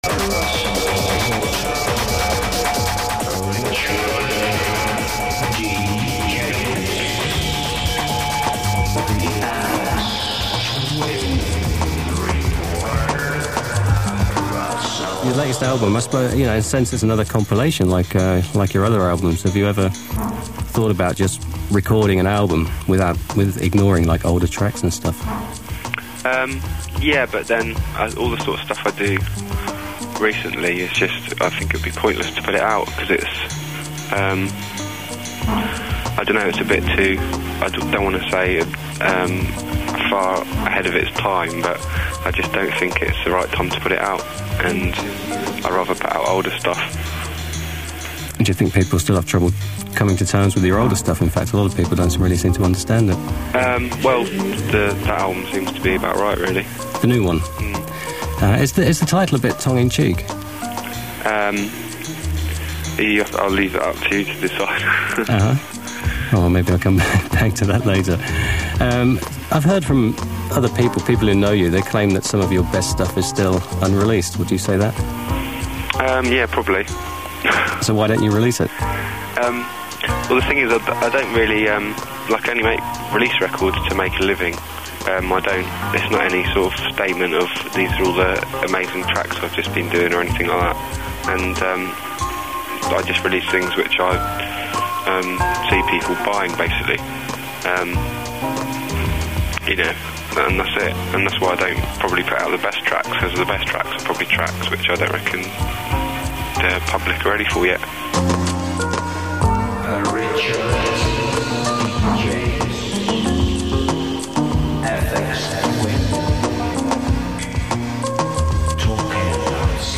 Download this file File Information Views 793 Downloads 39 Submitted November 20, 2014 Published January 6, 2015 Updated January 6, 2015 File Size 9.01 MB date 1995-25-05 location Netherlands-Hilversum venue VPRO